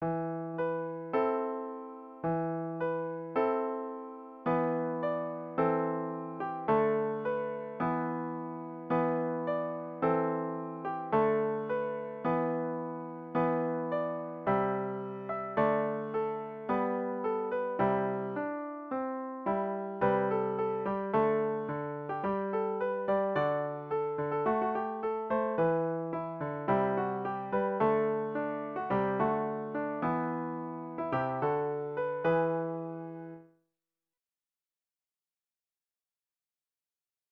Children’s Choir and Piano